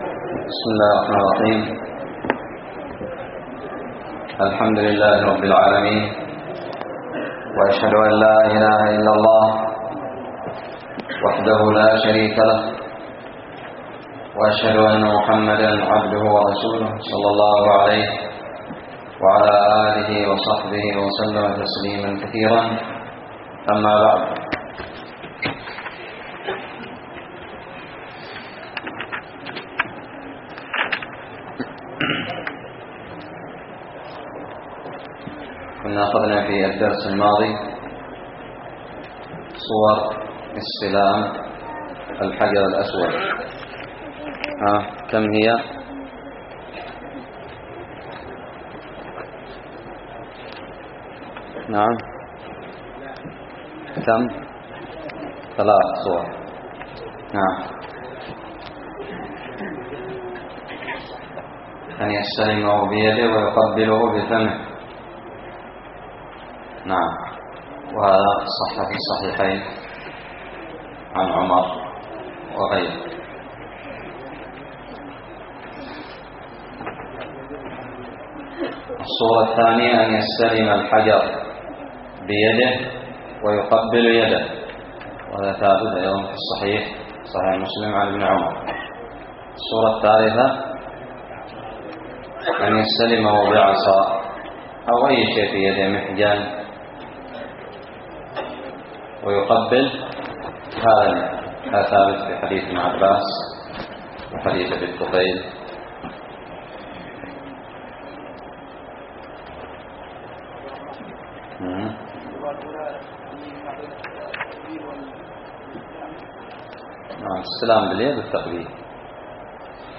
الدرس الثاني والأربعون من كتاب الحج من الدراري
ألقيت بدار الحديث السلفية للعلوم الشرعية بالضالع